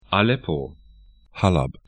Pronunciation
Aleppo a'lɛpo Halab 'halab ar Stadt / town 36°12'N, 37°10'E